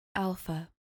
Pronounced: AL-far